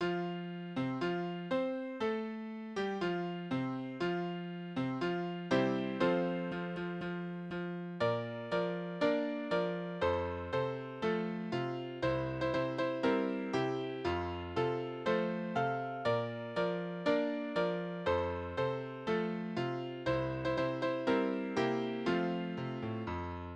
Reservistenlieder: Garnison
Tonart: F-Dur
Taktart: 4/4
Tonumfang: Oktave, Quarte
Besetzung: vokal